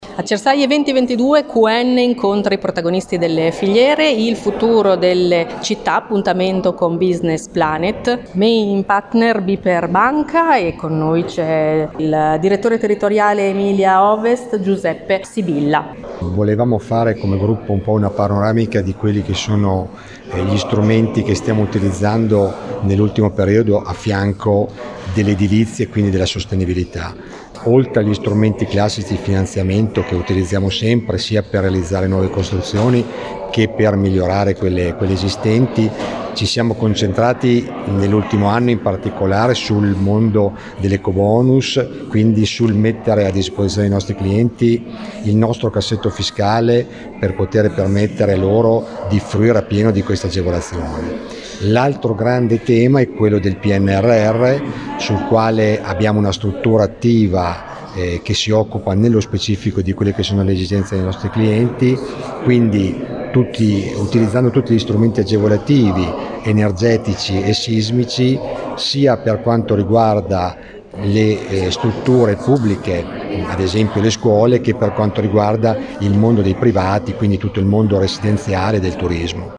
l’intervista a